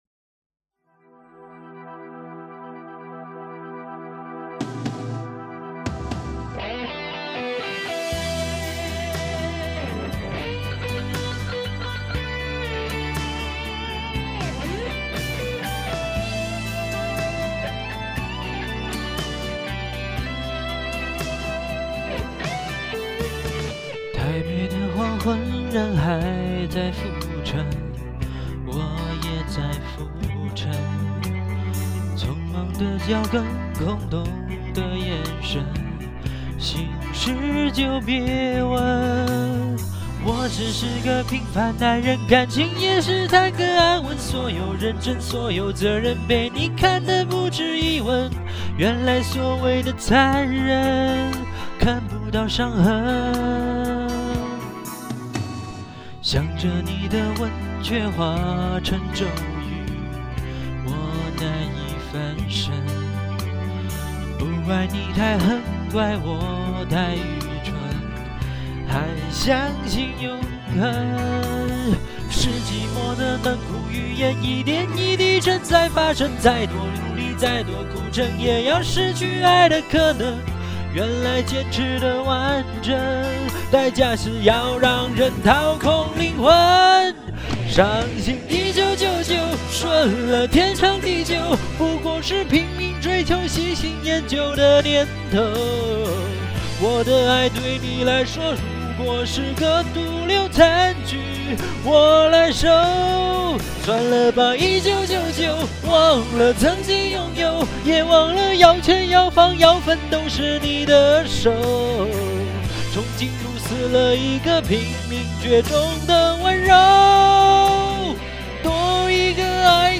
主要是太懒了，所以这首没消噪，就调了下音量。